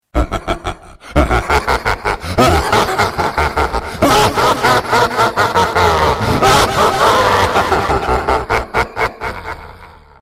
Sisyphus Laugh Botón de Sonido